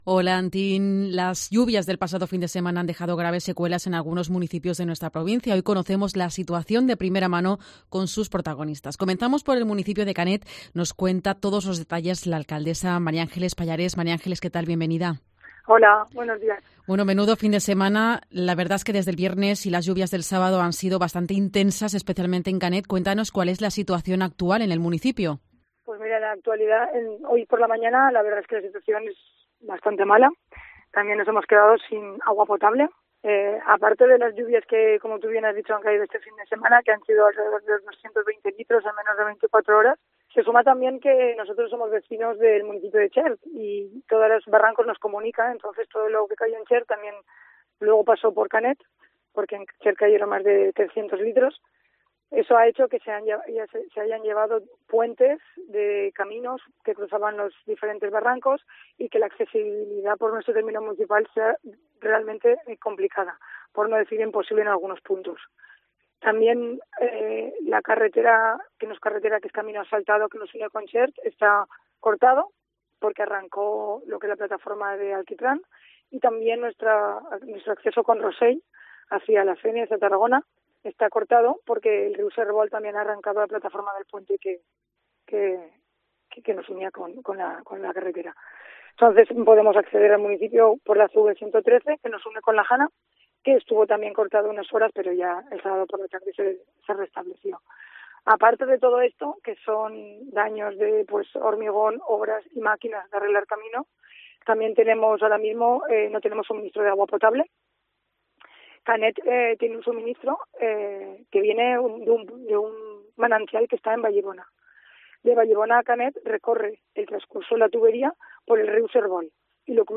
Entrevista a la Alcaldesa del municipio de Canet Lo Roig, sobre las consecuencias del temporal.